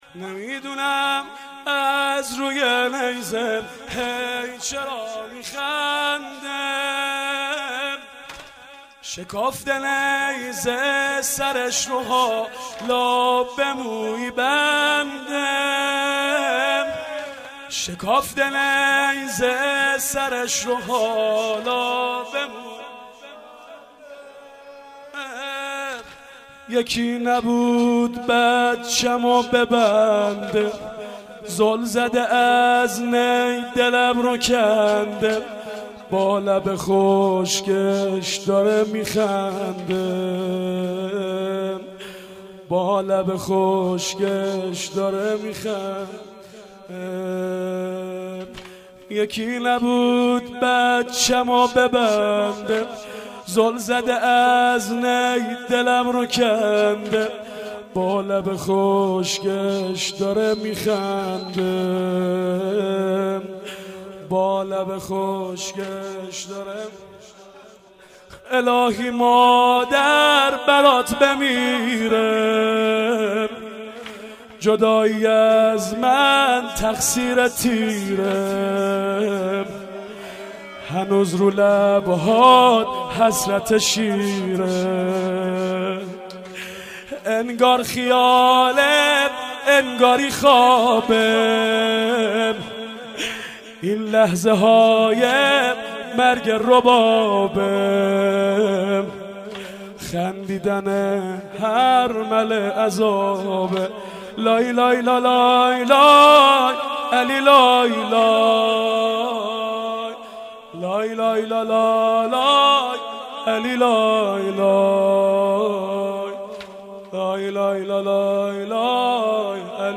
نمي دونم از روي نيزه هي چرا مي خنده ... واحد سنگين ...